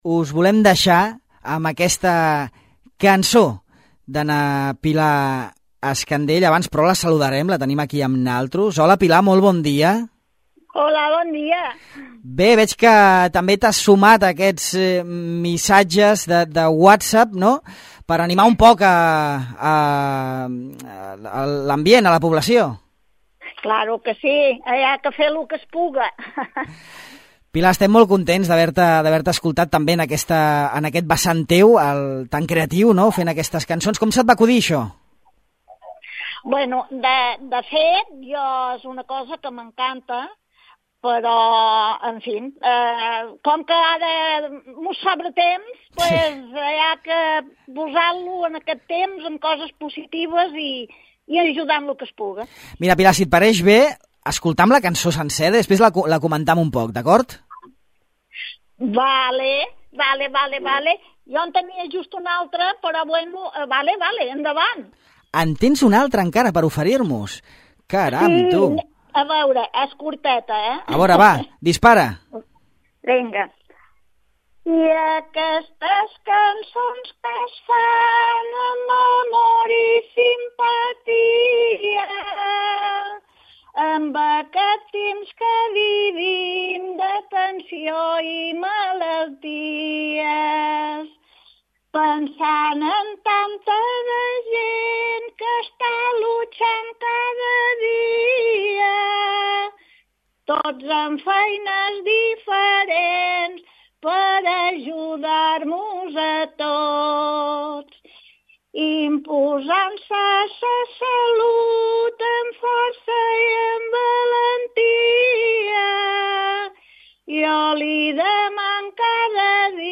La cançó pagesa
que ens visita via telefònica
per cantar-nos un parell de cançons